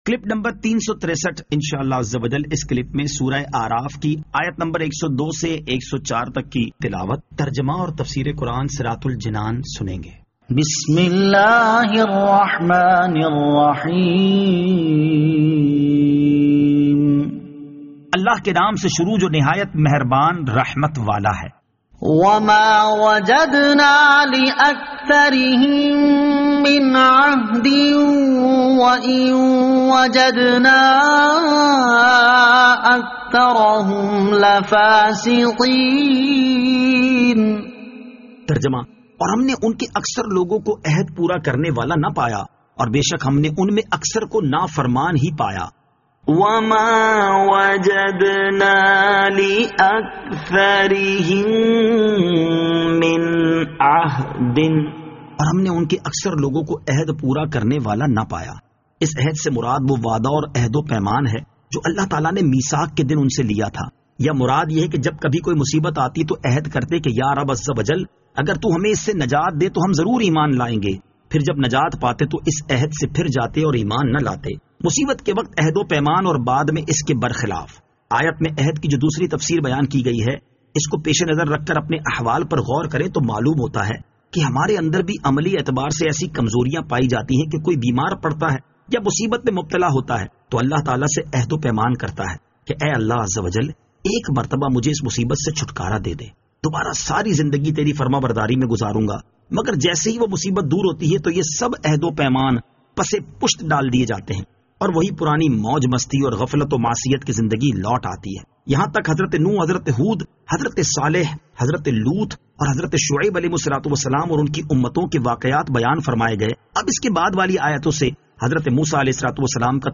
Surah Al-A'raf Ayat 102 To 104 Tilawat , Tarjama , Tafseer